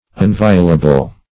Unviolable \Un*vi"o*la*ble\, a.